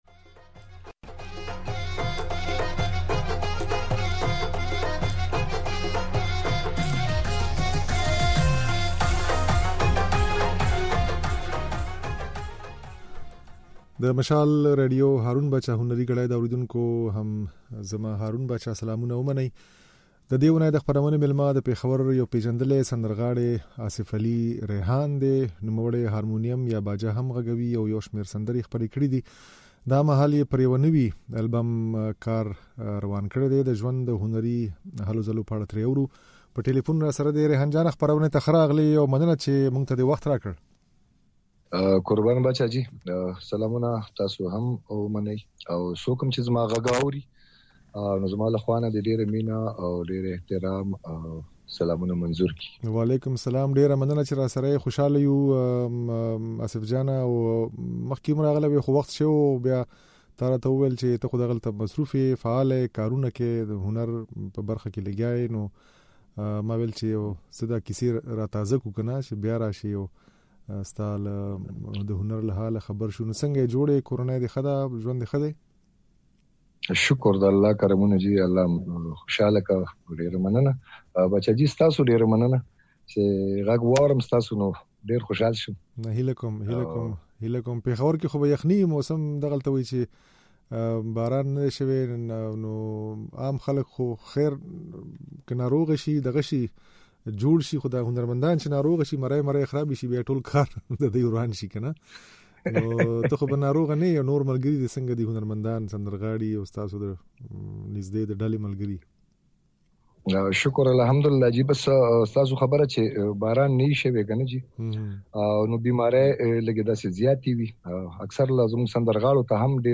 هارمونيم غږوونکی او سندرغاړی
دا خبرې او ځينې سندرې يې په خپرونه کې اورېدای شئ.